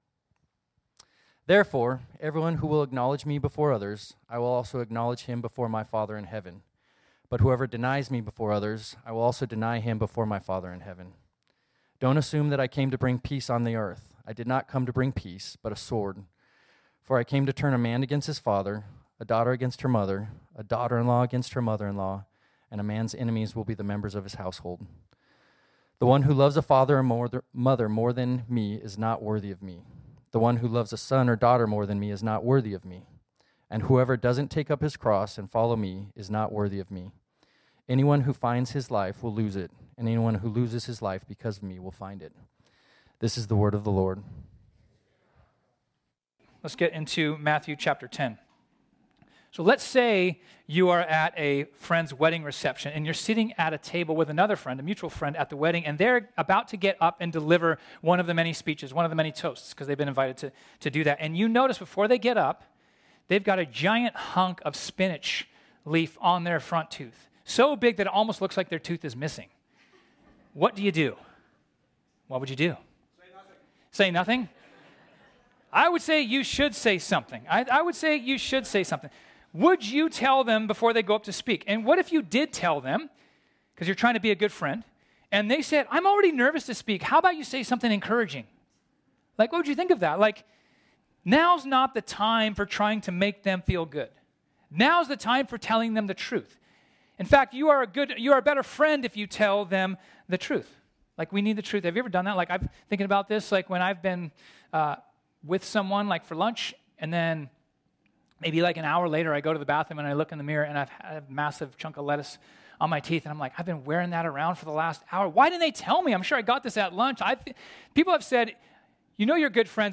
This sermon was originally preached on Sunday, May 5, 2024.